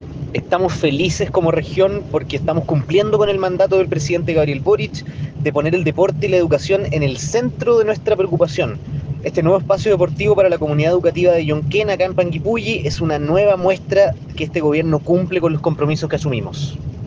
Llonquen-Seremi-Gobierno.ogg